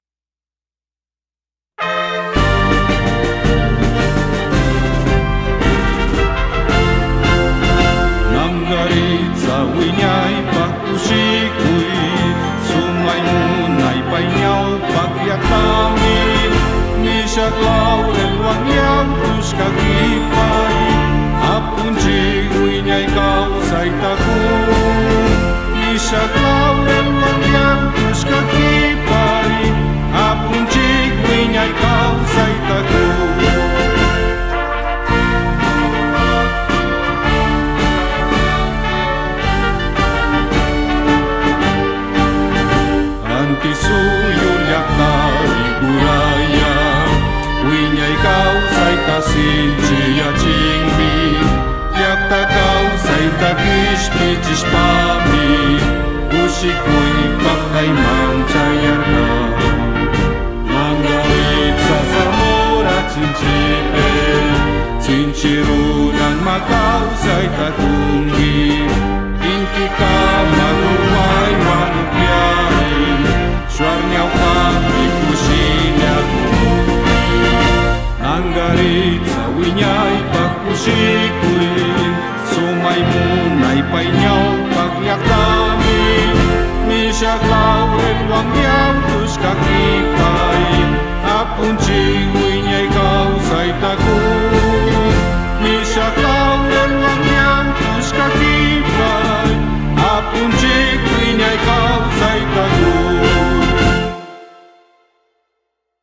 Himno del Cantón Nangaritza